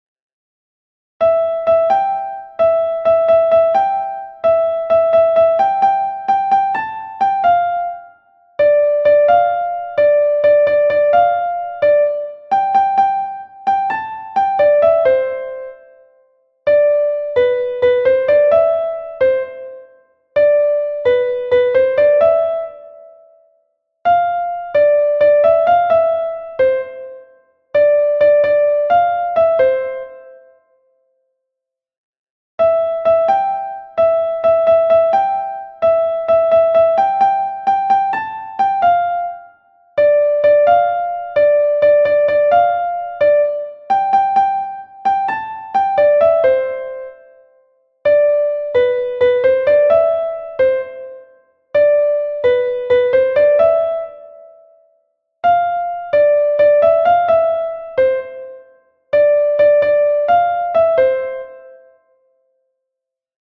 vaquina_piano.mp3